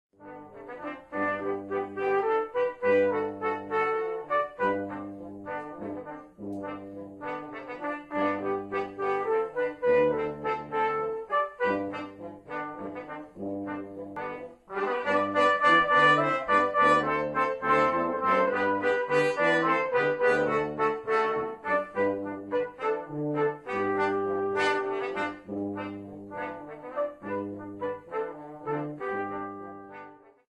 Einstimmiger Chorgesang zu 86 deutschen Volksliedern.
Probenmitschnitt